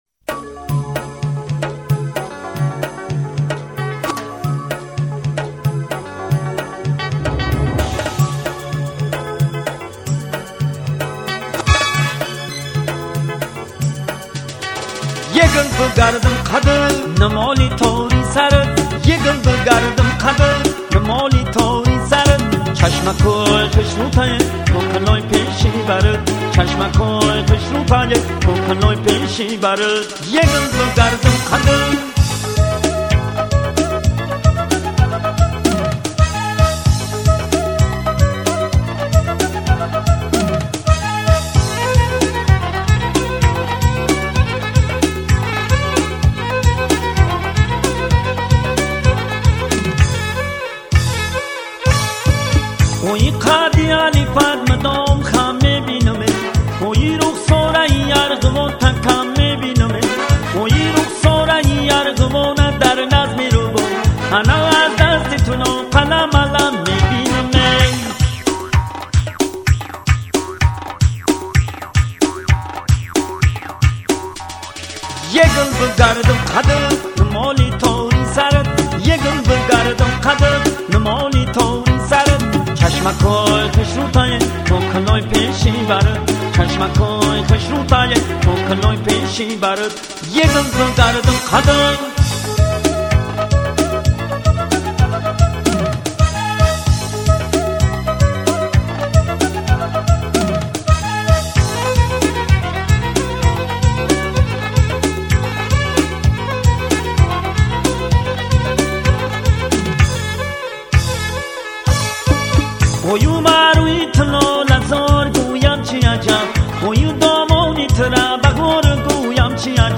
хонандаи тоҷик